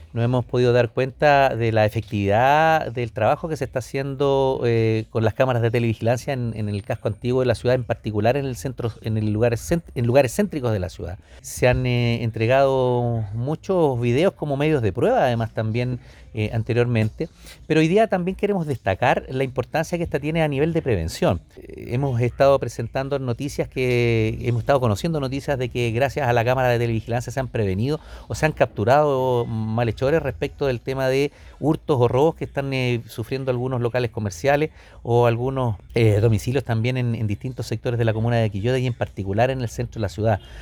Sobre el trabajo desarrollado se refirió el alcalde Óscar Calderón,